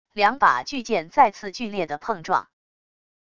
两把巨剑再次剧烈的碰撞wav音频